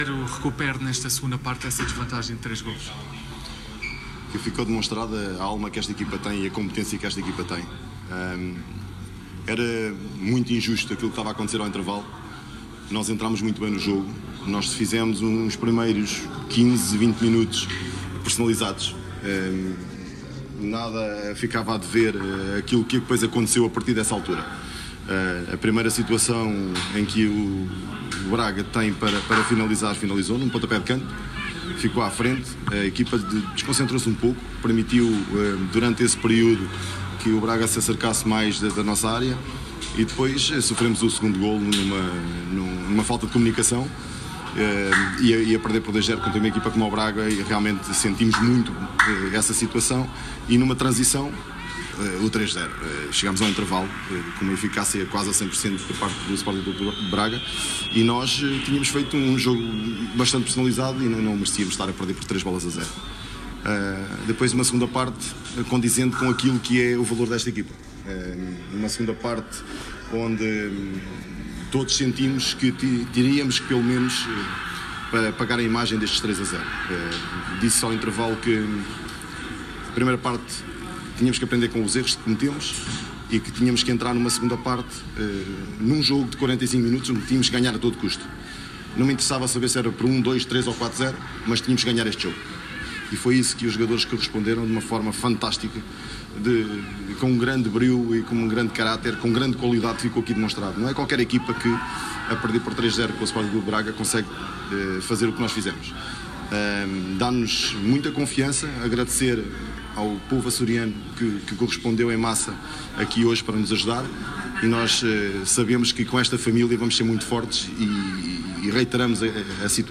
em declarações na conferência de imprensa após o empate frente ao Sp. Braga, na 2.ª jornada da Liga: